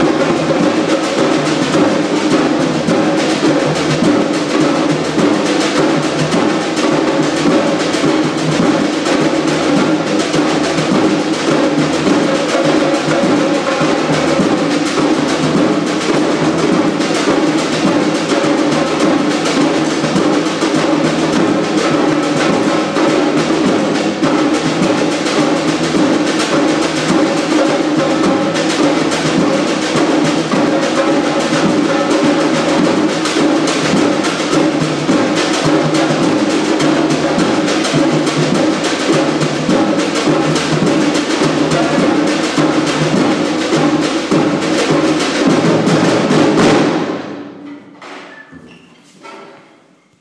Samba rehearsal
Samba